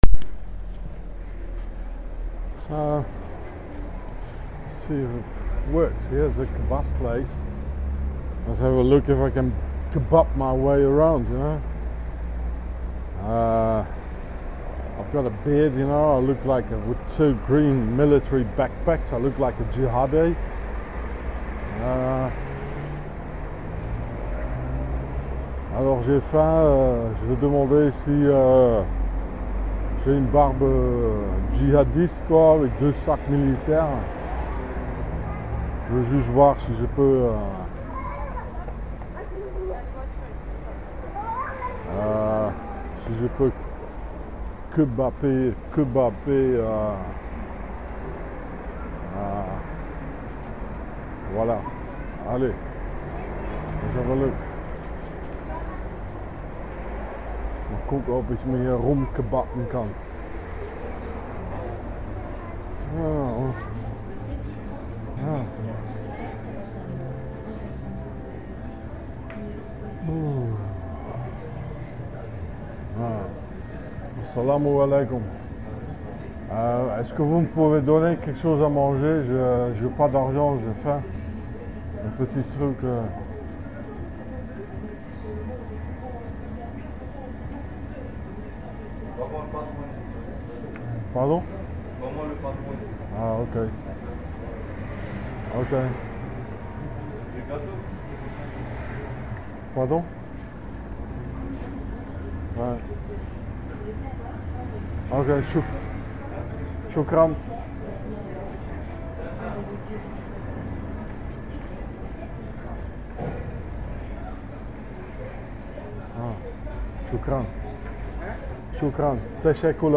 POOR MAN ASKING FOR SOME CHOW; UN PAUVRE DEMANDE DE LA BOUFFE